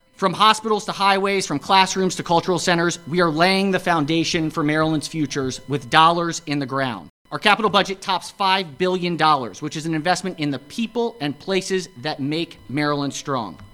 Senate President Bill Ferguson spoke about monies budgeted for capital projects…